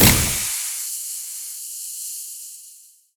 poly_explosion_smoke02.wav